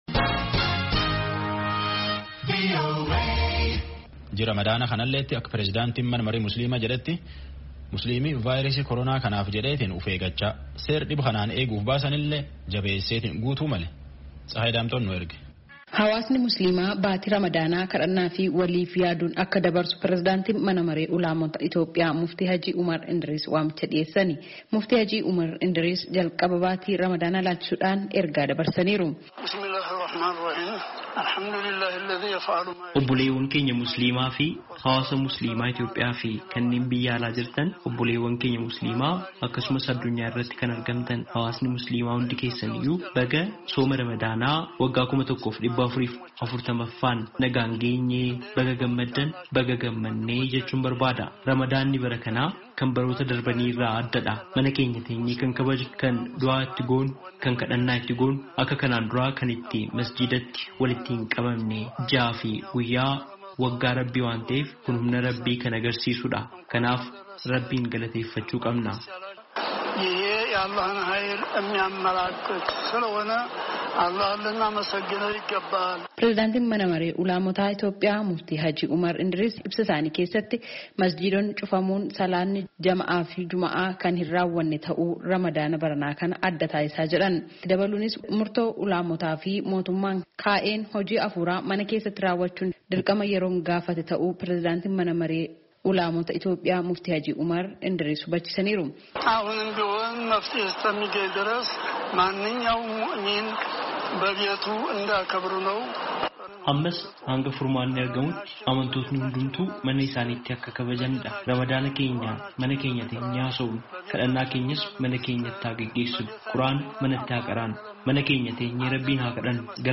Prezidaantii Mana Maree Ulamoota Ityoophiyaa Muftii Hajii Umar Edriis sooma Ramadaanaa ilaalchisuun har'a ibsa kana kennan.
Gabaasaa guutuu caqasaa.